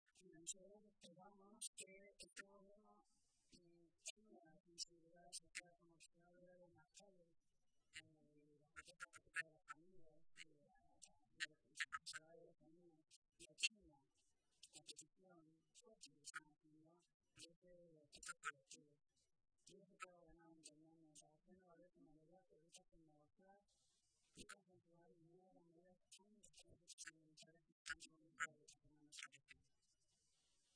Mari Carmen Rodrigo, portavoz de Educación del Grupo Sacialista
Cortes de audio de la rueda de prensa